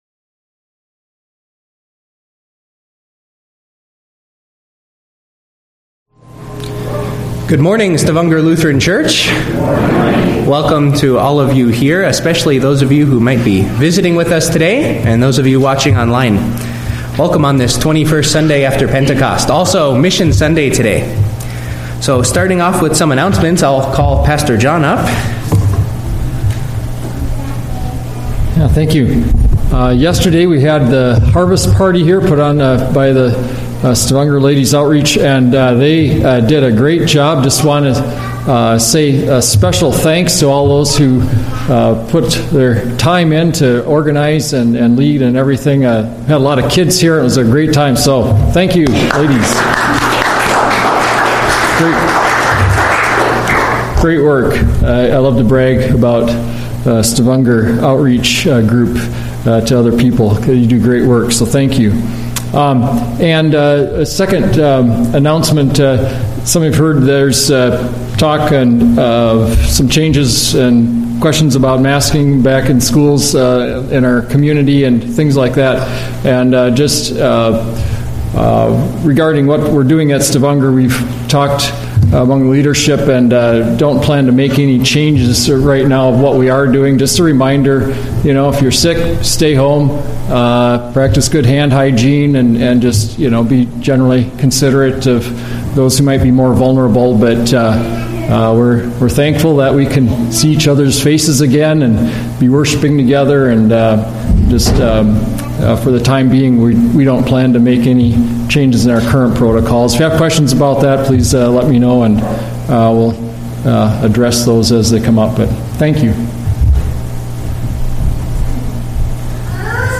A message from the series "Sunday Worship." The Hard Way - Mark 10:23-31
From Series: "Sunday Worship"